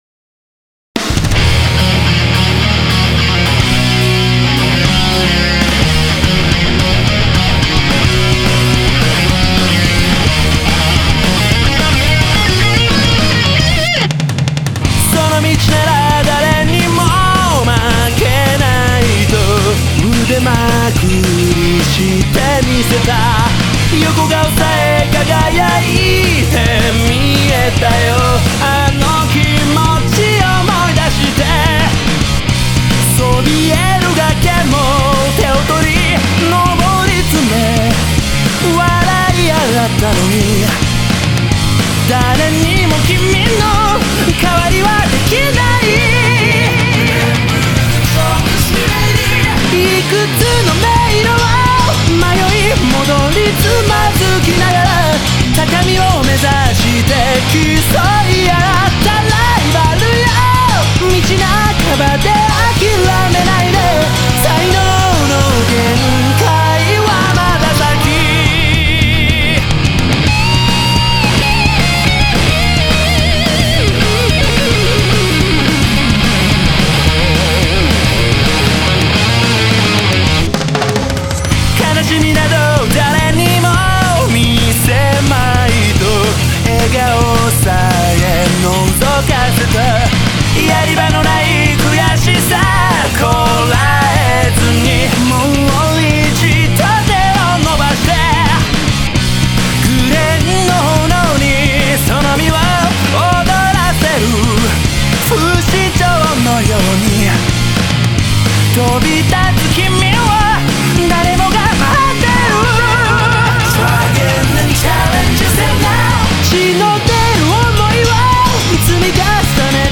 原曲より一層アグレッシブな仕上がりになっています。